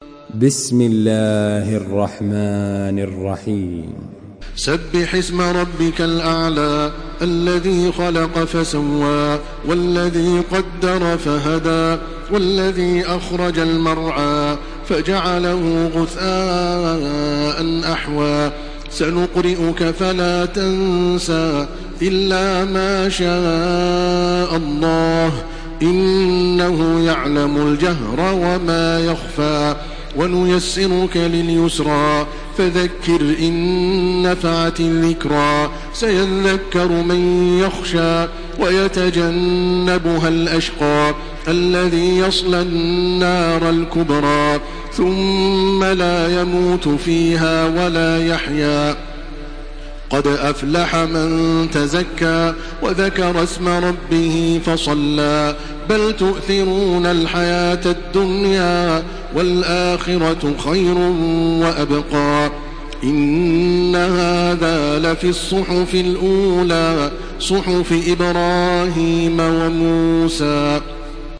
Surah Ala MP3 by Makkah Taraweeh 1429 in Hafs An Asim narration.
Murattal